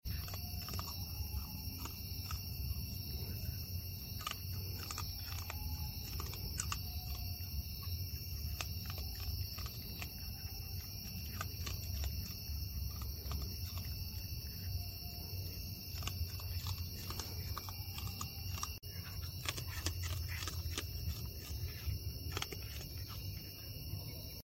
ASMR De Un Mapache 🦝 Sound Effects Free Download